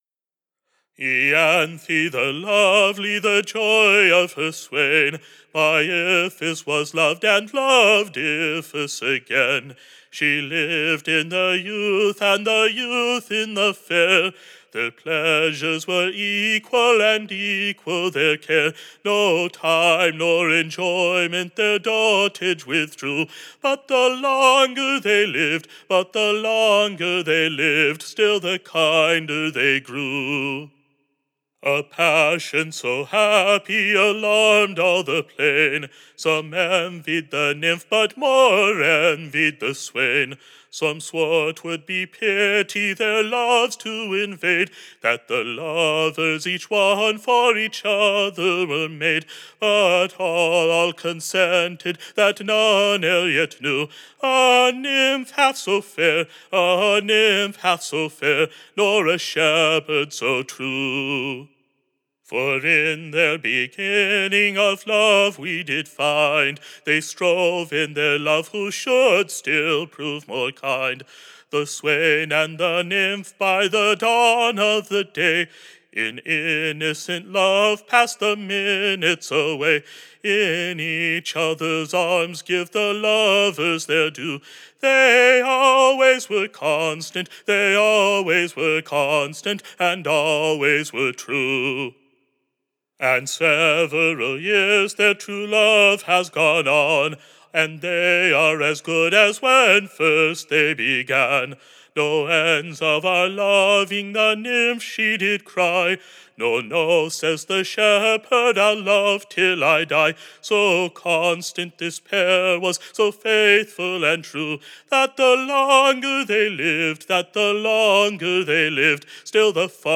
Recording Information Ballad Title The happy Pair. / A new Song, sung by Mr. Abell, Tune Imprint Standard Tune Title Ianthe the Lovely Media Listen 00 : 00 | 7 : 27 Download r3.502.mp3 (Right click, Save As)